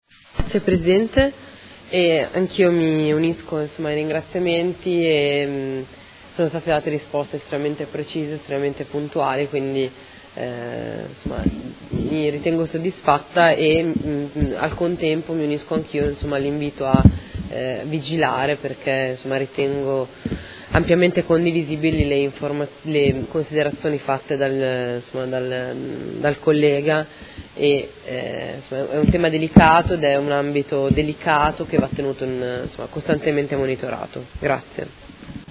Federica Di Padova — Sito Audio Consiglio Comunale
Conclude interrogazione della Consigliera Di Padova (PD) avente per oggetto: Sicurezza dei mezzi SETA, accadimento del 29 settembre 2018